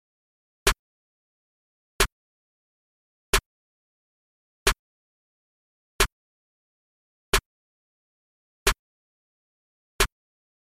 硬式陷阱鼓 小鼓
Tag: 90 bpm Trap Loops Drum Loops 1.79 MB wav Key : Unknown